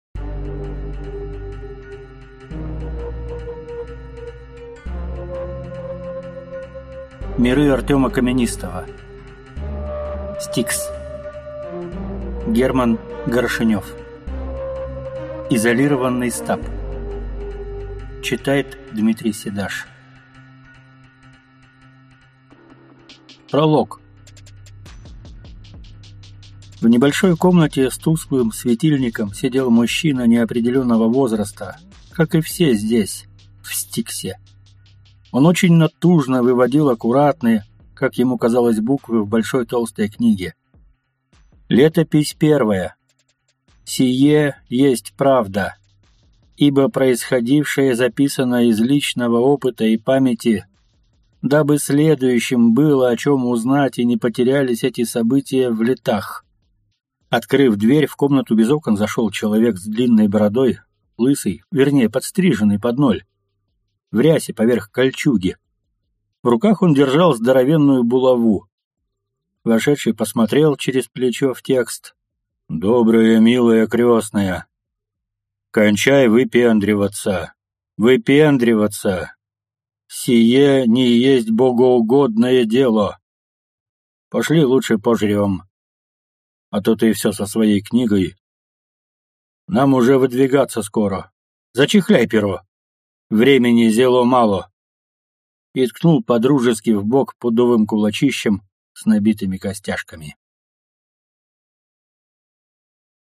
Аудиокнига S-T-I-K-S. Изолированный стаб | Библиотека аудиокниг